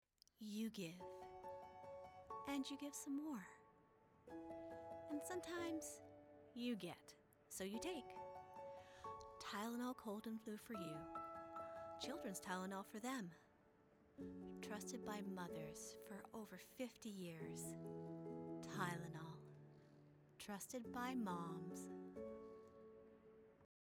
Female
Television Spots